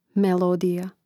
melòdija melodija